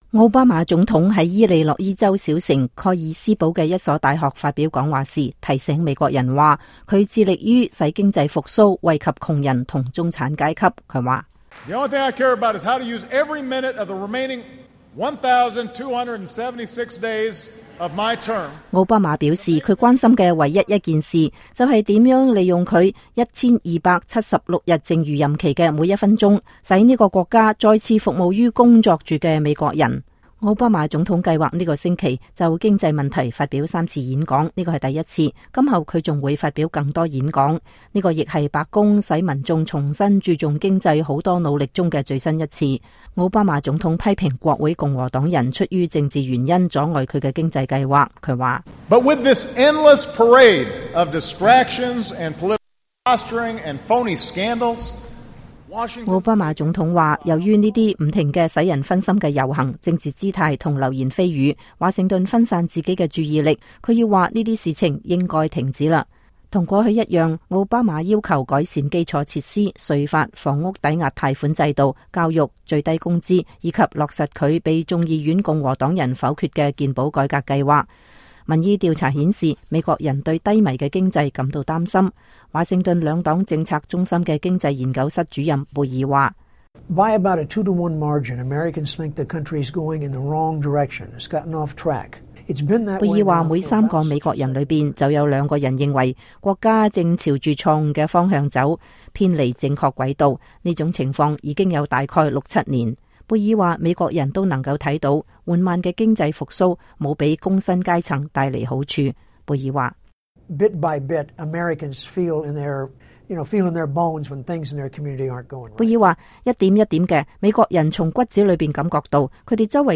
奧巴馬就美國經濟發表演講